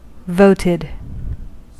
Ääntäminen
Ääntäminen US Haettu sana löytyi näillä lähdekielillä: englanti Käännöksiä ei löytynyt valitulle kohdekielelle. Voted on sanan vote partisiipin perfekti.